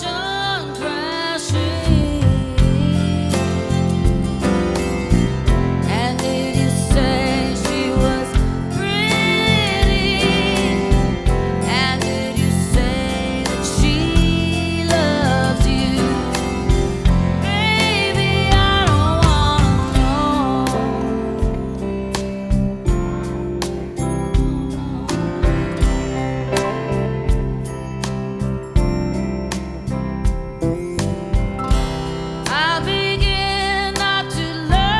Жанр: Поп музыка / Рок
Rock, Pop, Soft Rock, Arena Rock